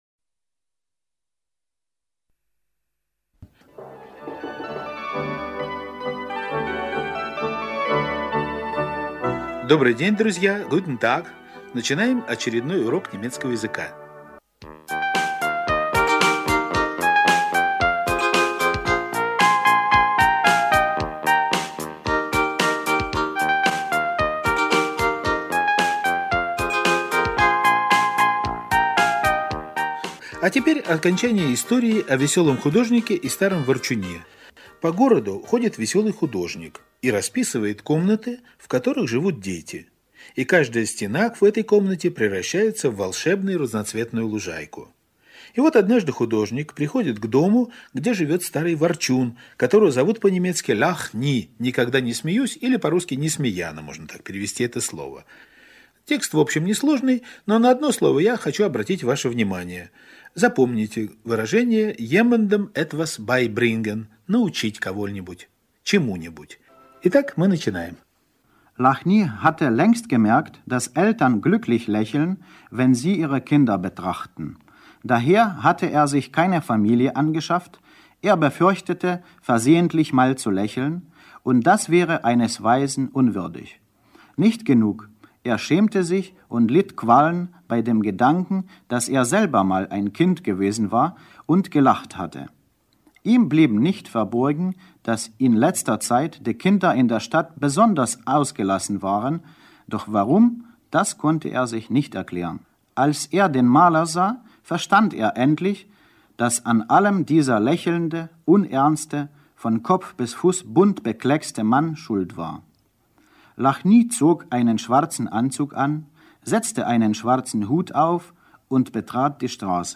Углубленный аудиокурс немецкого языка — часть 3
Файл аудио урока доступен для скачивания и прослушивания в режиме онлайн.